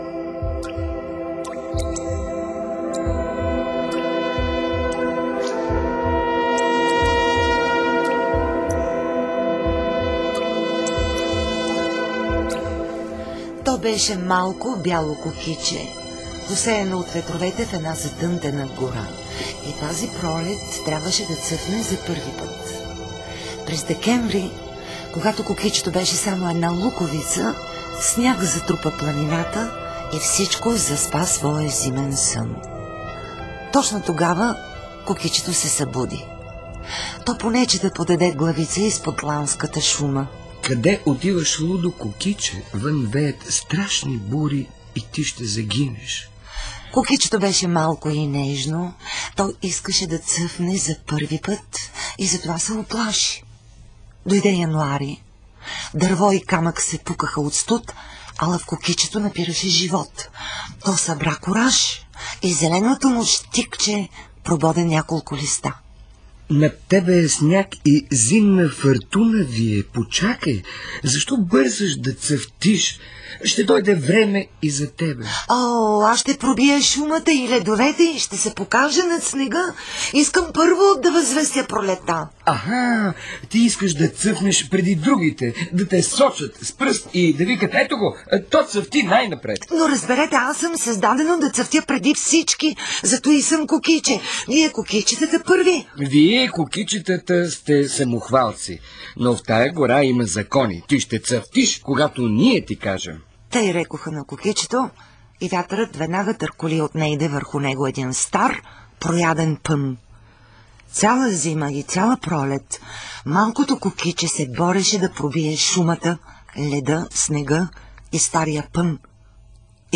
кокиче_през_август--разказ--радио.mp3